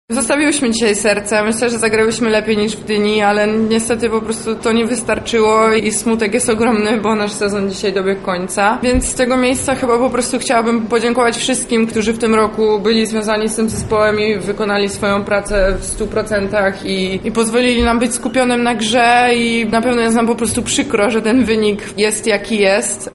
mówiła na konferencji prasowej